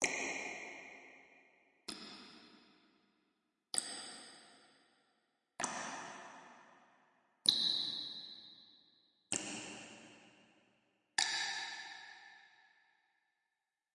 描述：滴水的高效果录音。可作为洞穴或地牢场景的环境背景。
标签： 吓人 地下 回声 湿 洞穴 声音效果 氛围 戏剧 黑暗 气氛 混响 高铈 地牢 环境 电影 电影 洞穴
声道立体声